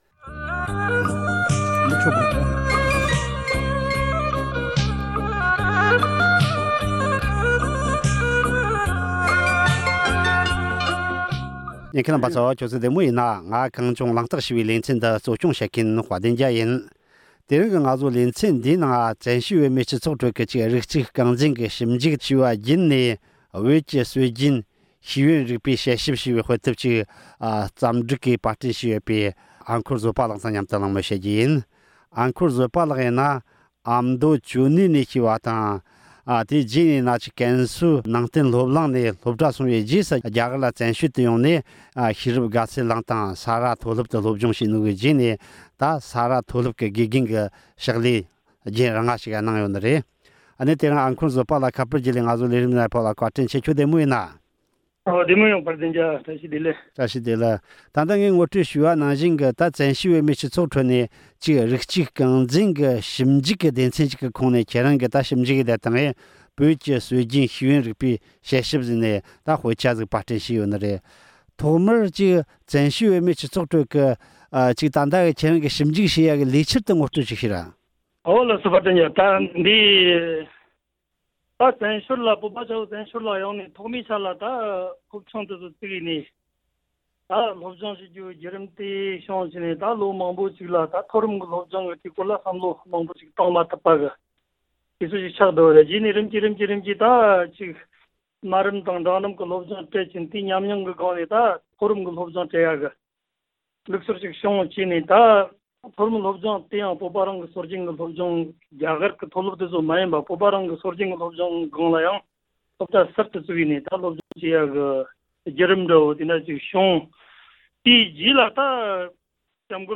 བོད་ཀྱི་སྲོལ་རྒྱུན་ཤེས་ཡོན་རིག་པའི་སྐོར་ལ་གླེང་མོལ་ཞུས་པ་གསན་རོགས་གནང་།